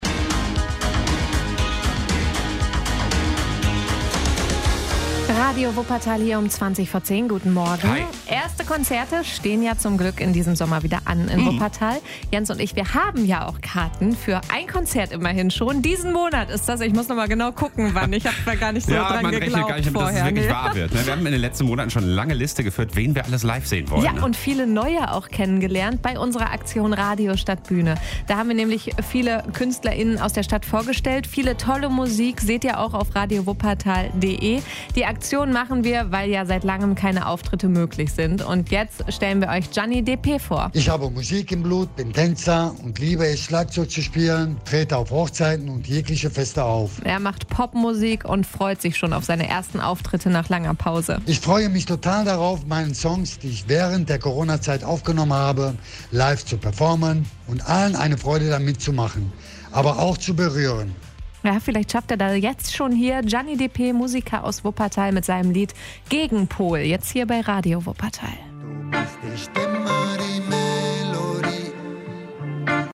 Pop-Musik.